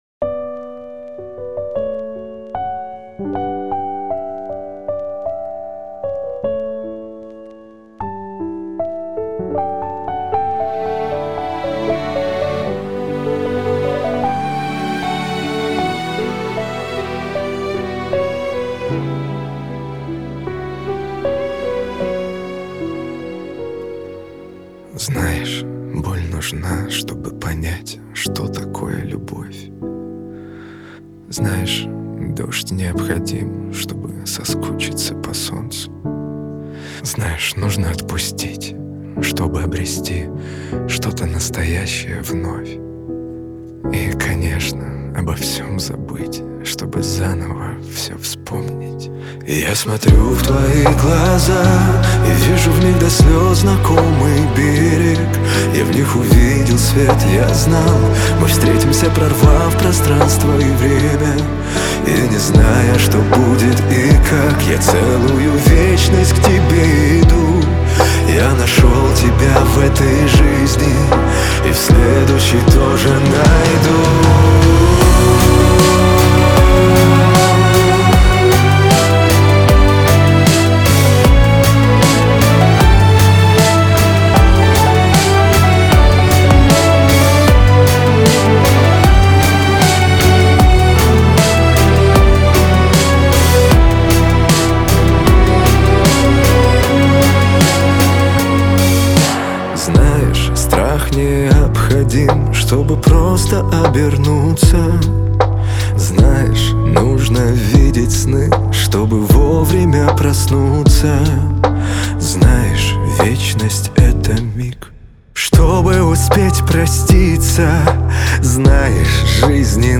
Категория Хип-хоп